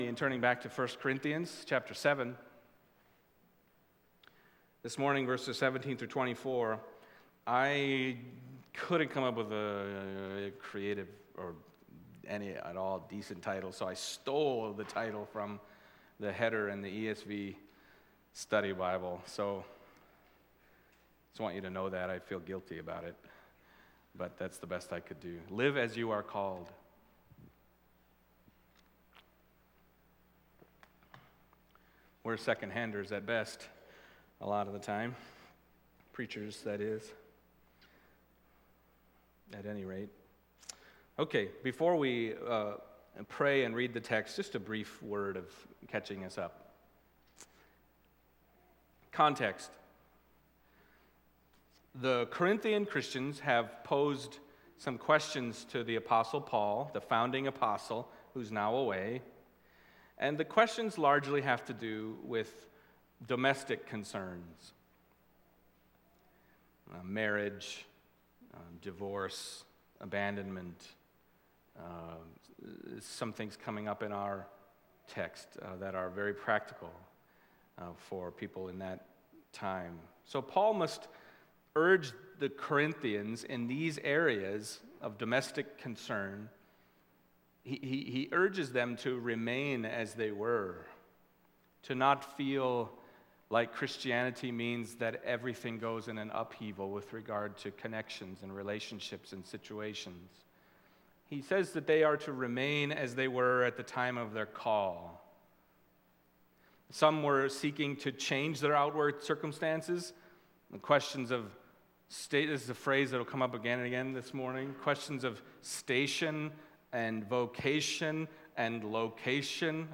Passage: 1 Corinthians 7:17-24 Service Type: Sunday Morning